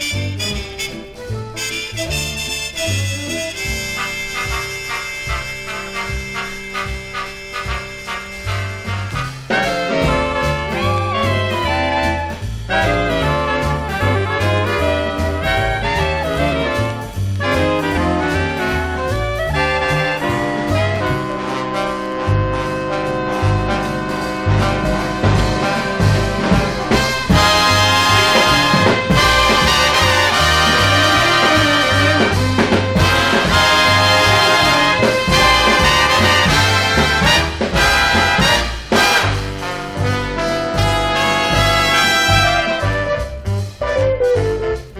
Jazz, Big Band, Cool Jazz　USA　12inchレコード　33rpm　Mono